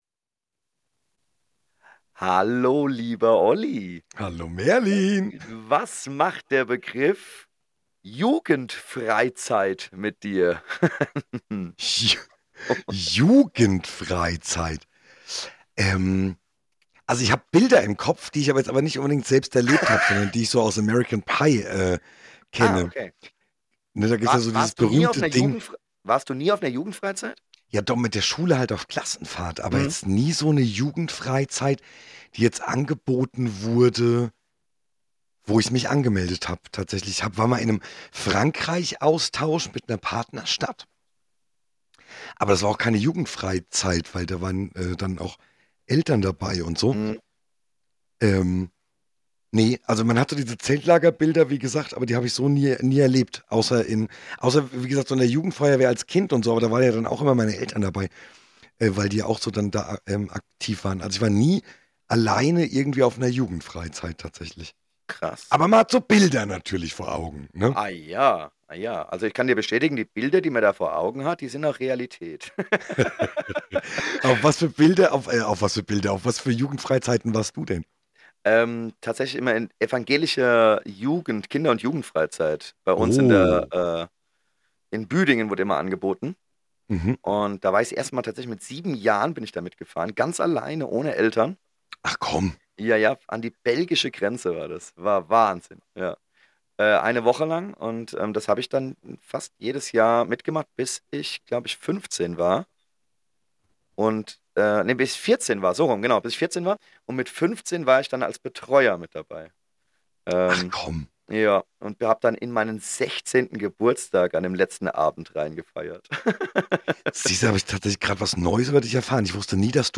Wie immer ungeschnitten und ohne Filter!